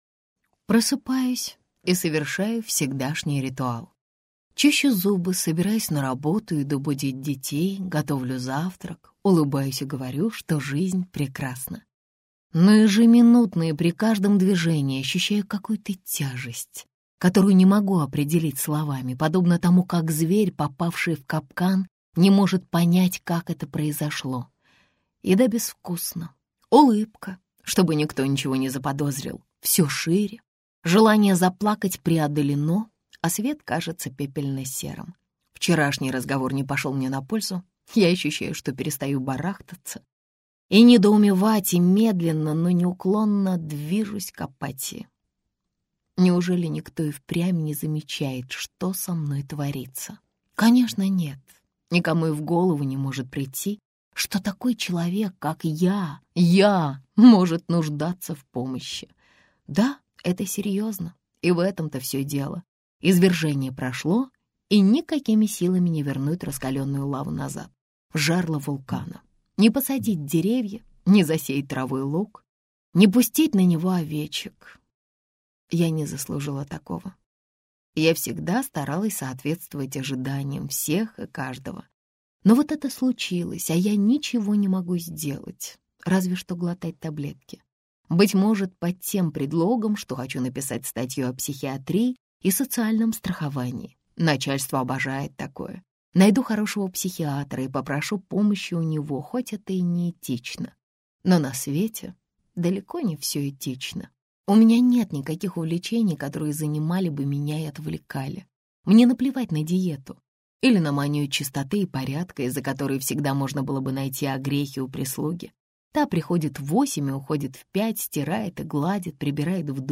Аудиокнига Адюльтер - купить, скачать и слушать онлайн | КнигоПоиск